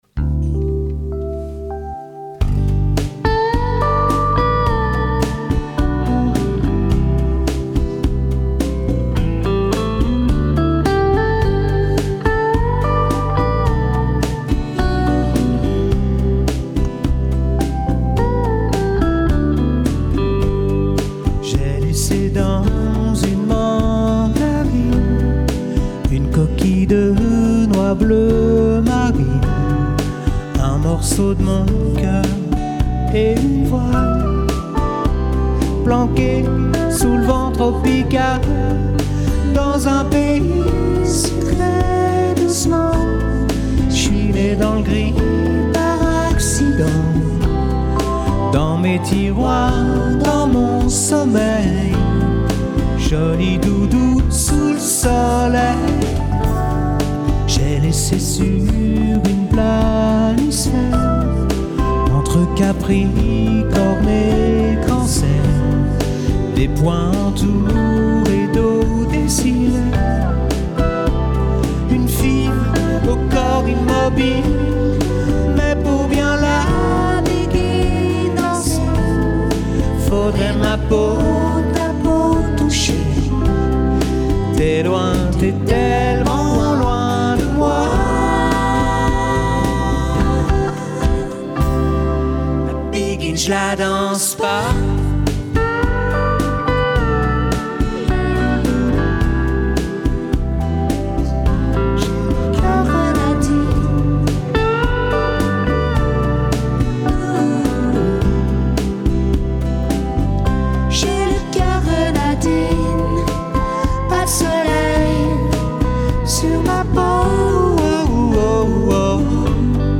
Programme d'orchestre de variété avec chanteuse et chanteur